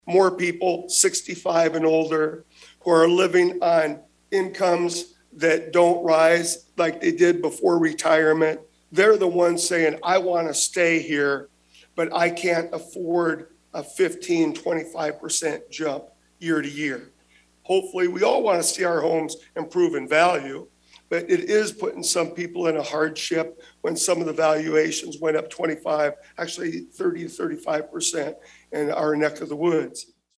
The legislators made their comments during a forum sponsored by the Iowa Taxpayers Association.